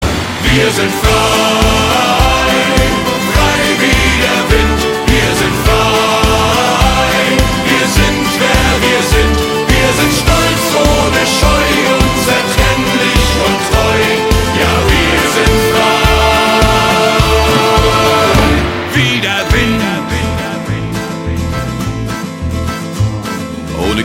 Gattung: Moderner Einzeltitel
Besetzung: Blasorchester
Blasmusik-Ausgabe (mit Gesang)
Tonart: A-Moll